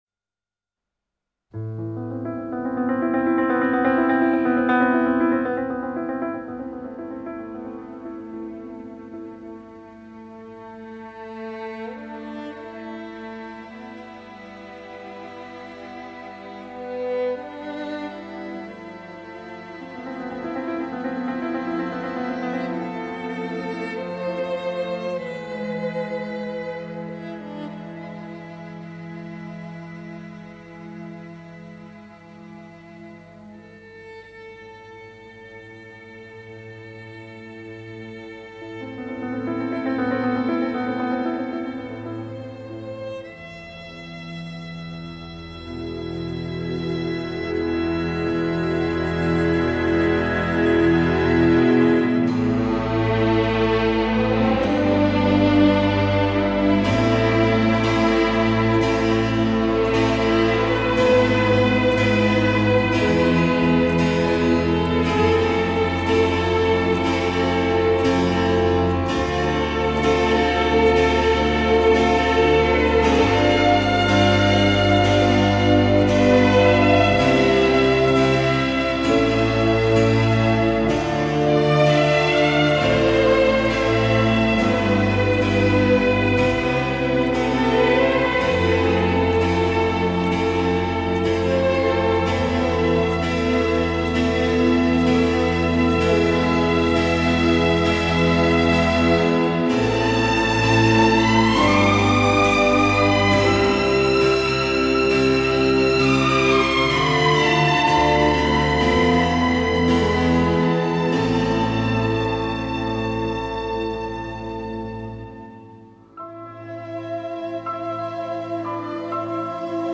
Κάθε Δευτέρα και Τετάρτη βράδυ στις έντεκα, ζωντανά στο Τρίτο Πρόγραμμα